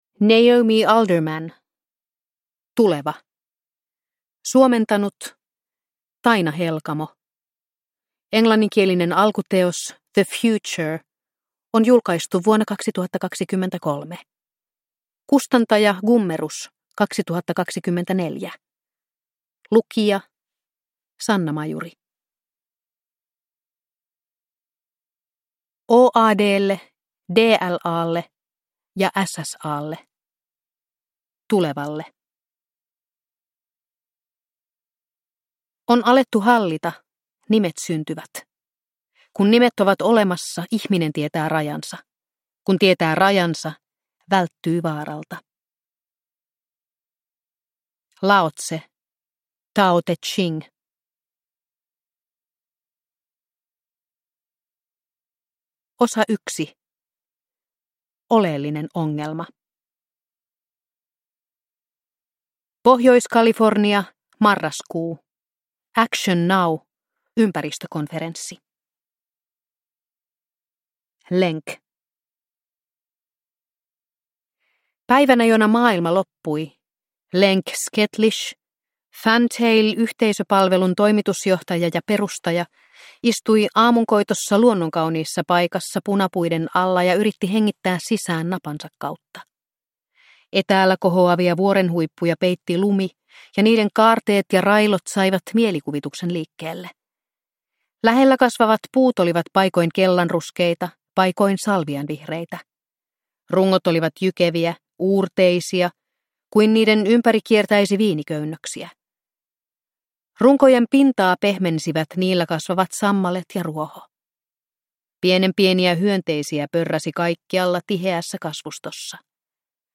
Tuleva – Ljudbok